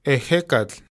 Ehecatl (Classical Nahuatl: Ehēcatl [eˈʔeːkatɬ],
modern Nahuatl pronunciation) is a pre-Columbian deity associated with the wind, who features in Aztec mythology and the mythologies of other cultures from the central Mexico region of Mesoamerica.